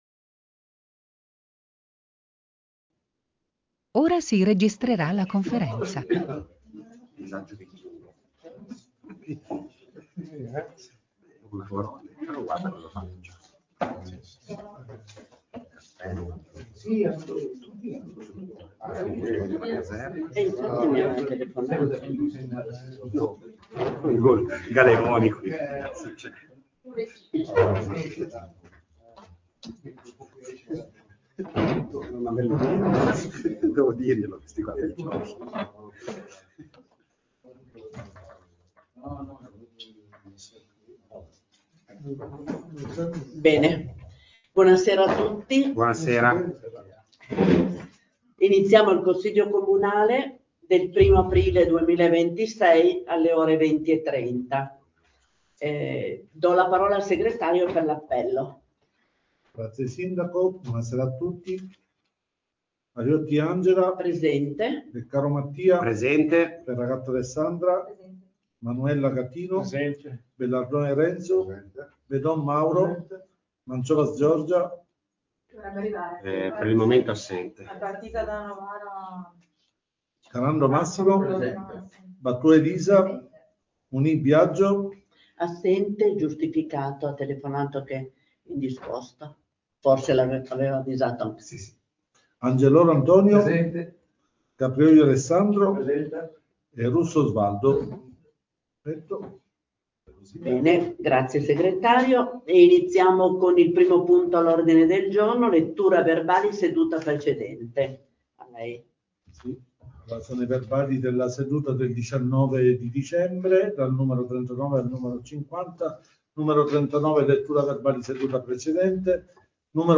Comune di Santhià - Registrazioni audio Consiglio Comunale - Registrazione Seduta Consiglio Comunale 1/04/2026